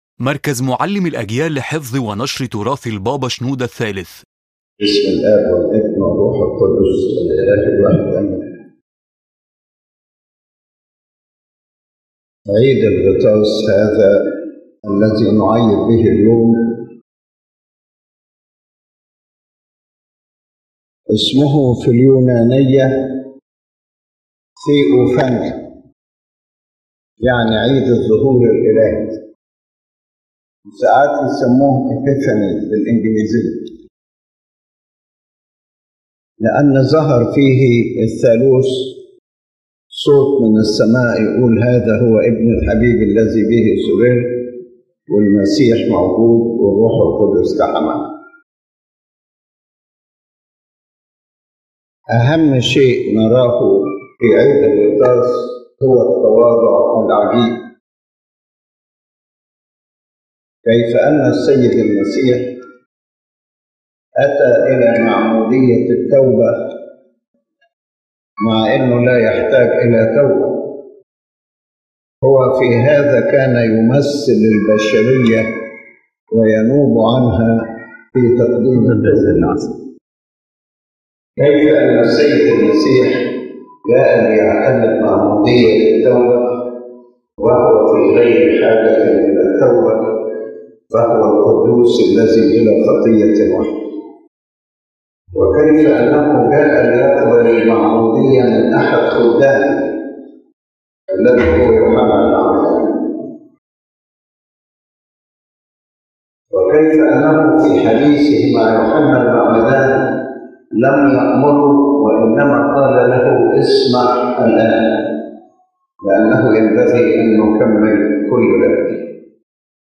عظة قداس عيد الغطاس المجيد